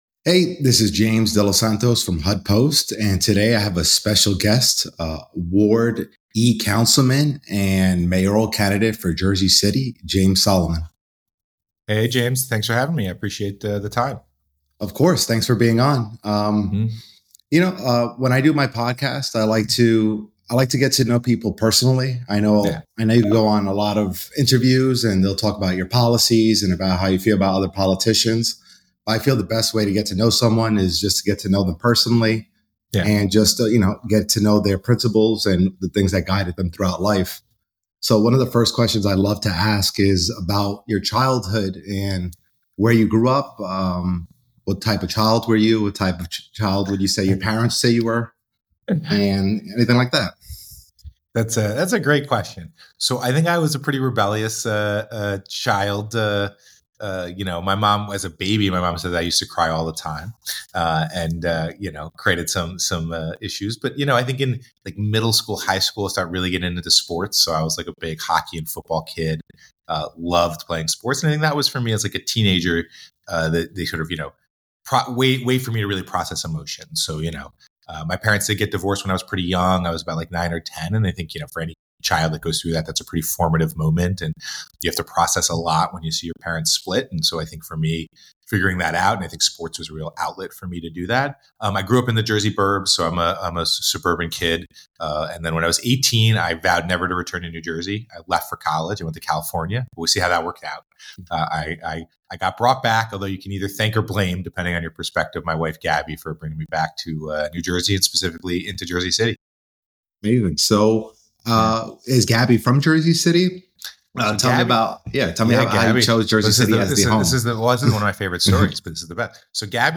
Interview with Councilman James Solomon